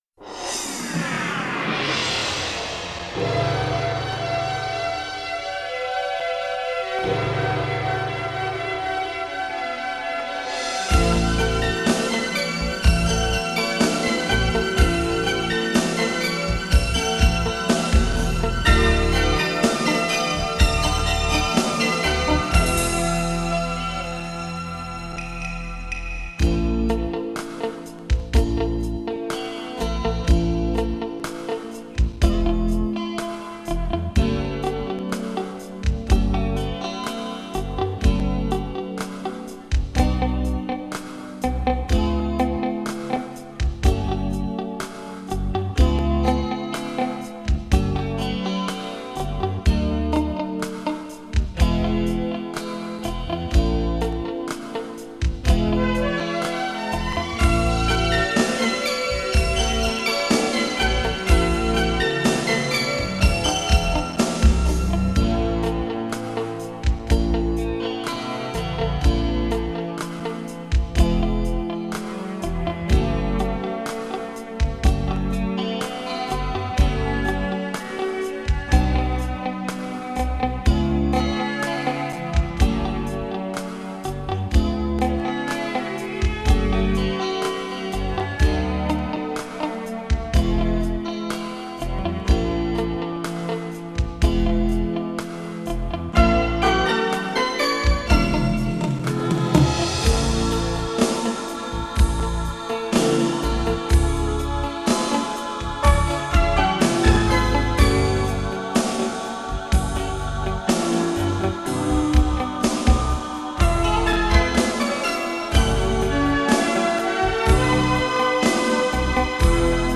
调式 : 降B 曲类